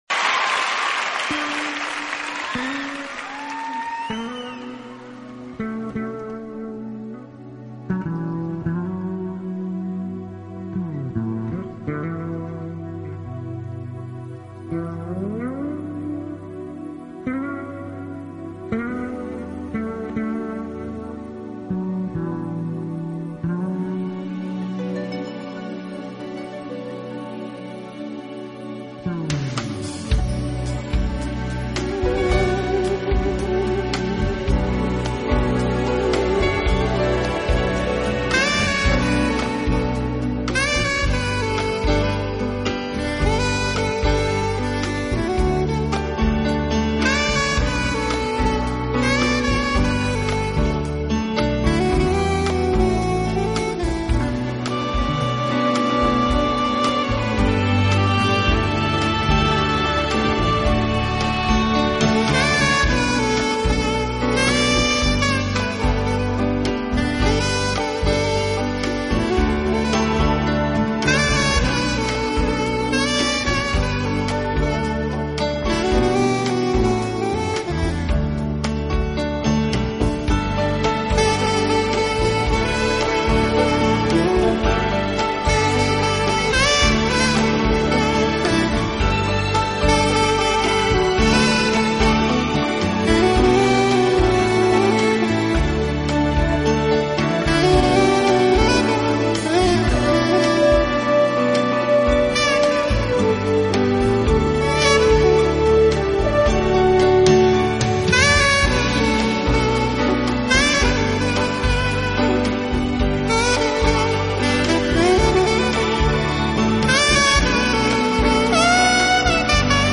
音乐类型：  New Age
钢琴演奏技巧，乐观、浪漫、激扬的主题，抒情优美的旋律，强大的制作演